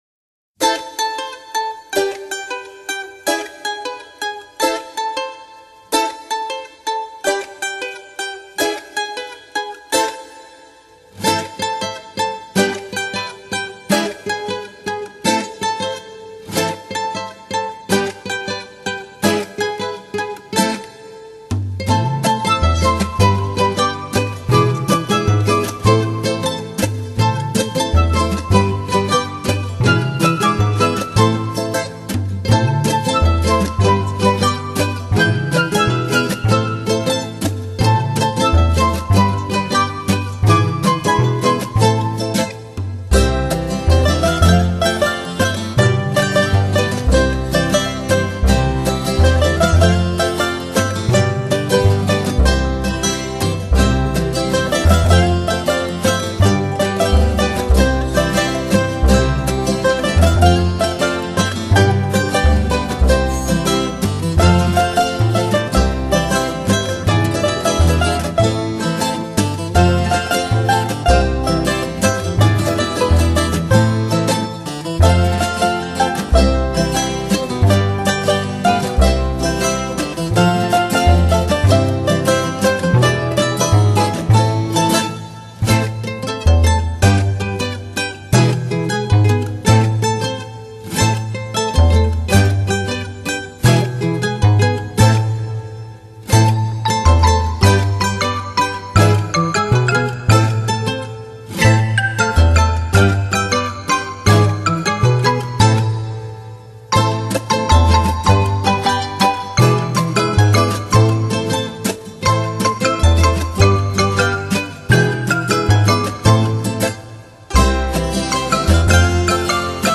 本片结合吉普赛人流浪的情结，加上东方的迷幻多情，使整张专辑营造出一幕幕幽远意象，如泣如诉地演奏出感人动听的旋律。